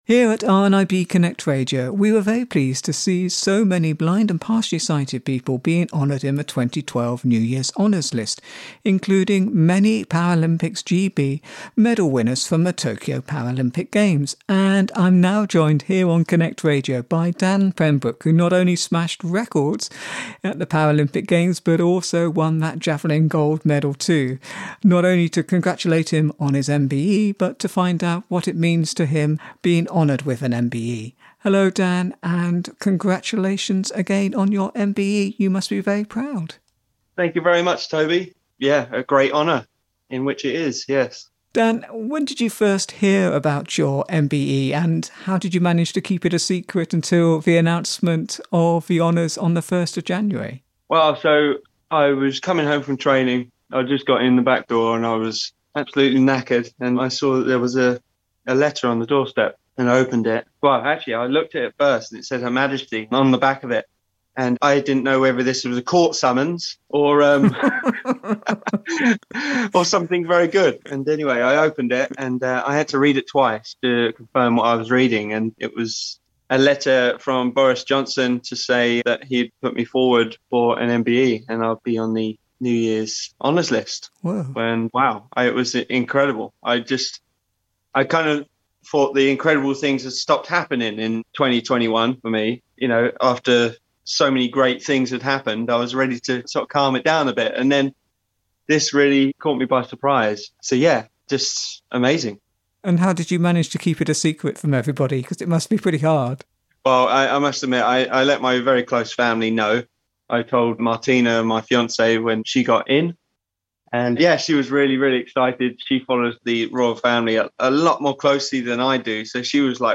Dan also chatted about his competition plans for 2022, entering the ‘able bodied’ British Championships and his ambition to make history as the first Paralympic athlete to win the able bodied British Championships which would be a big statement for Paraathletes.